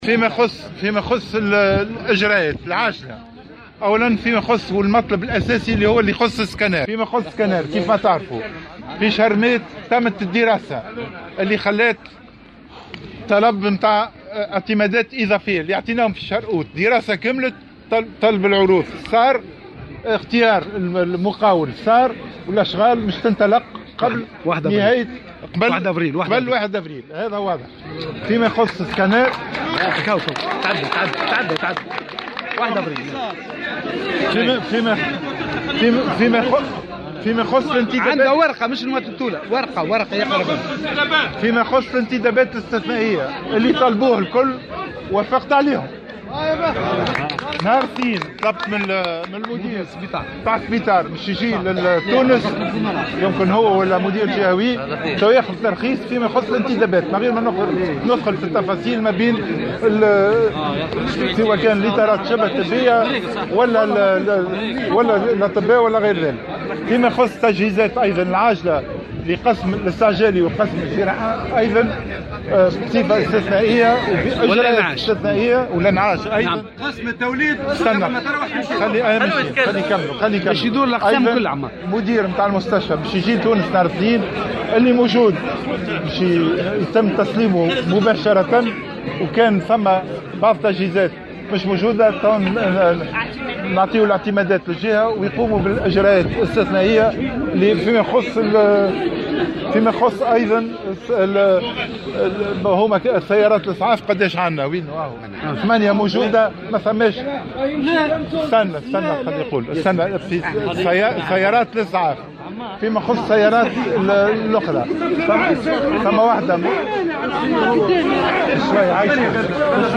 Lors d’une visite à l’hôpital régional de Ben Guerdane, effectuée ce samedi 12 mars 2016, le ministre de la santé Saïd Aïdi a annoncé une batterie de mesures d’urgence au profit de cet hôpital.
Ecoutez la déclaration du ministre pour tous les détails